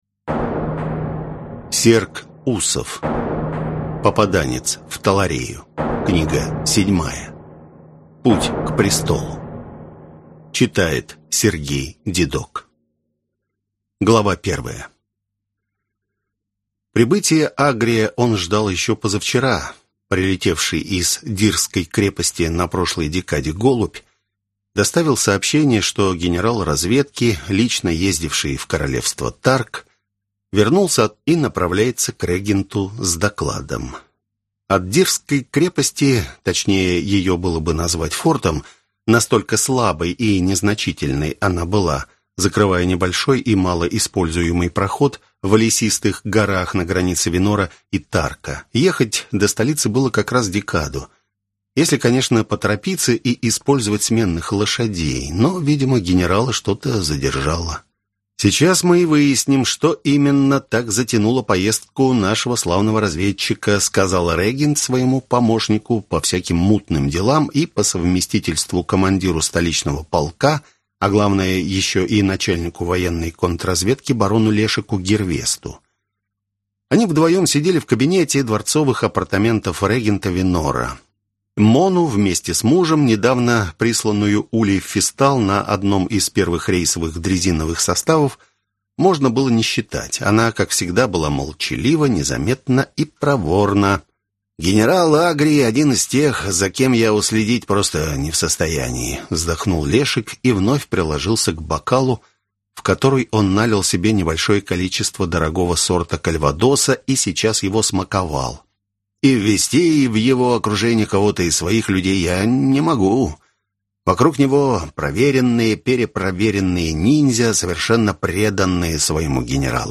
Аудиокнига Путь к престолу | Библиотека аудиокниг